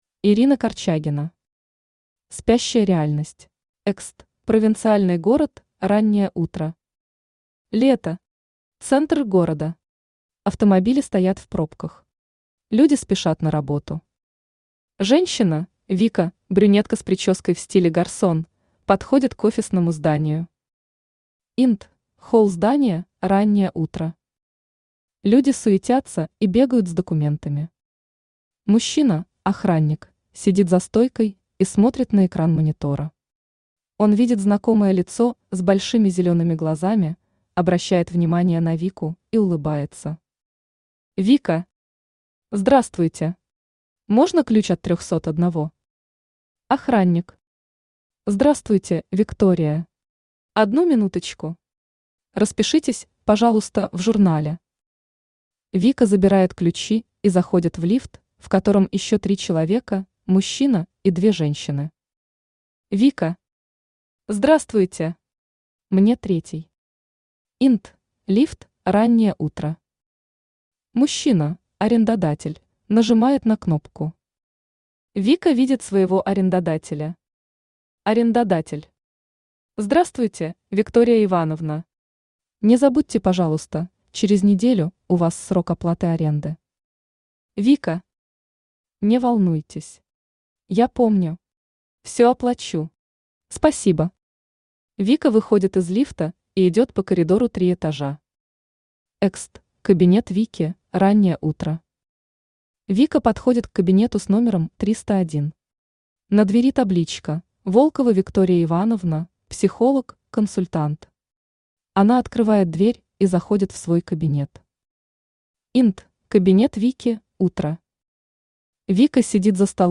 Аудиокнига Спящая реальность | Библиотека аудиокниг
Aудиокнига Спящая реальность Автор Ирина Юрьевна Корчагина Читает аудиокнигу Авточтец ЛитРес.